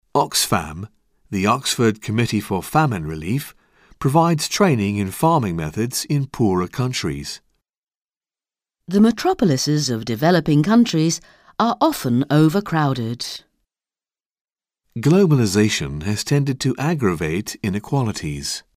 Un peu de conversation - Les relations internationales